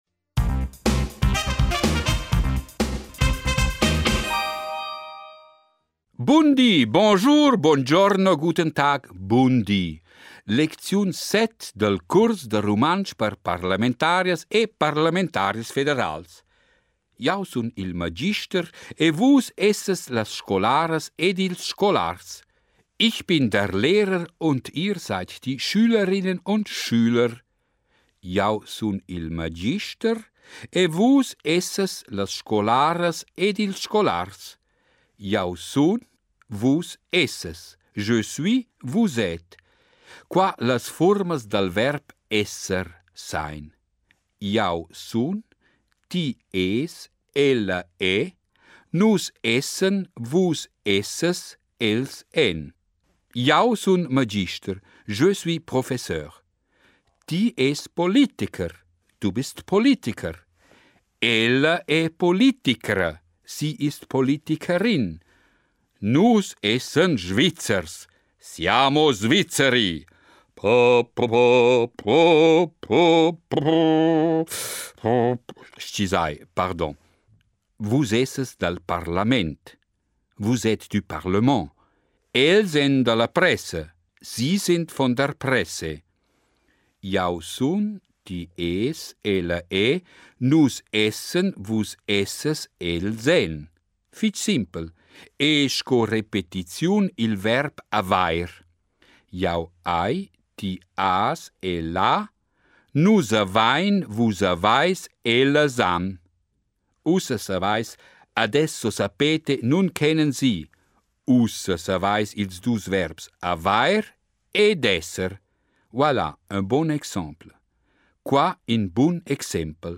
Extrait d’un CD publié par RTR et destiné à apprendre le romanche aux parlementaires participant à la session de Flims.